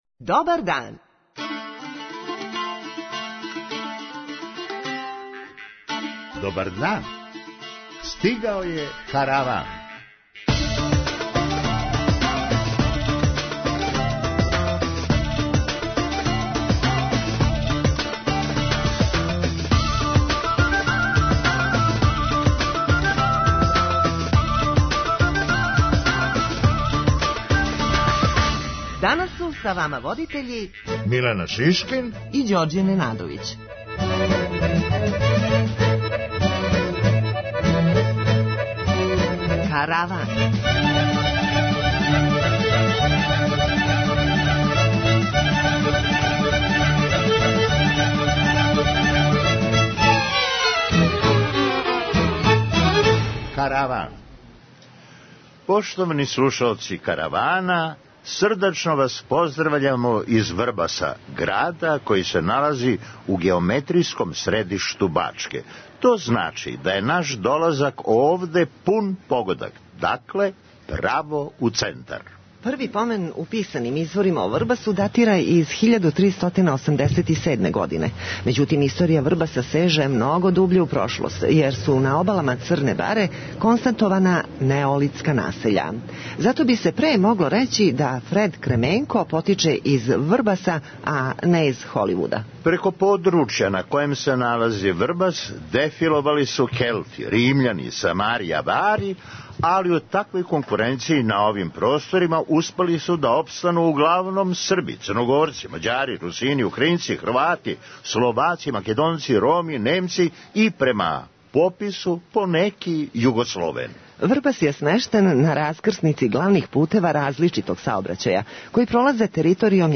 Поштовани слушаоци Каравана, данас ћемо вас поздравити из Врбаса, града који се налази у геометријском средишту Бачке.
преузми : 9.18 MB Караван Autor: Забавна редакција Радио Бeограда 1 Караван се креће ка својој дестинацији већ више од 50 година, увек добро натоварен актуелним хумором и изворним народним песмама.